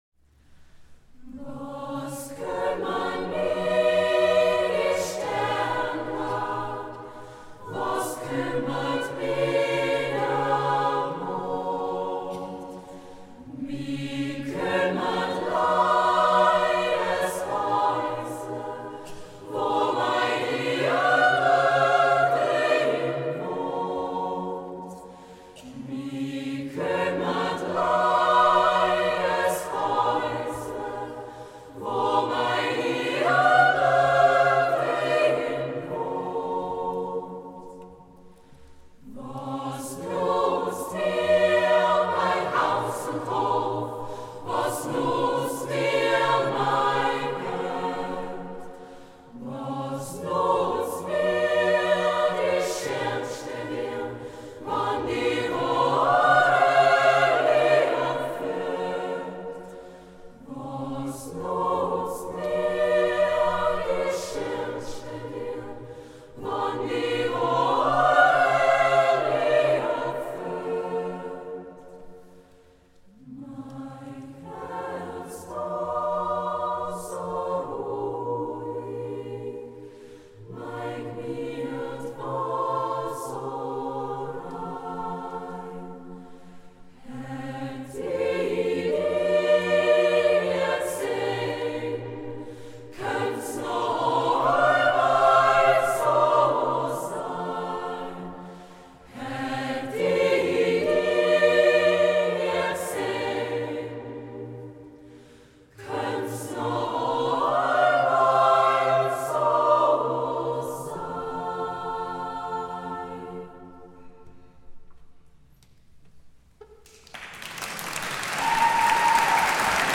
Tëuta su ai 27.04.2025 tla dlieja de Urtijëi